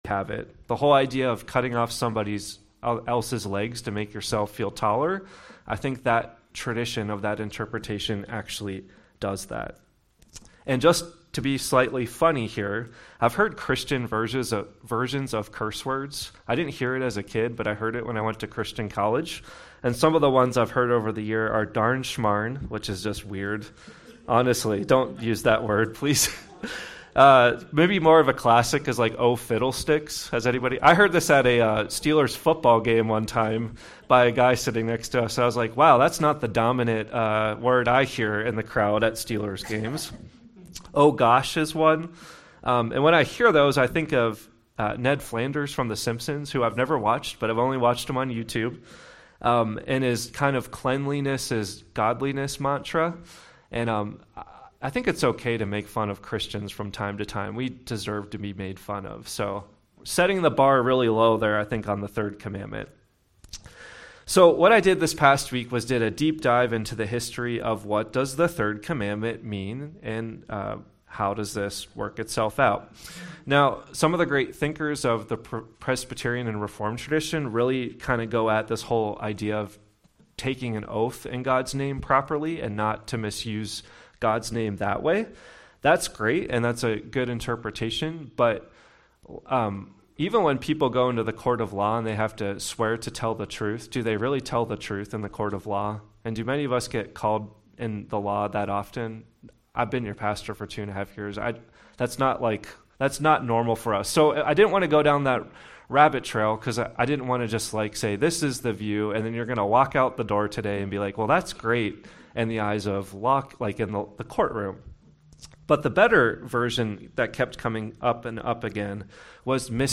Sermon text: Exodus 20:7
“The Third Commandment” Sorry for the late start of this week’s sermon recording…